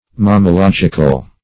Mammalogical \Mam`ma*log"ic*al\, a. Of or pertaining to mammalogy.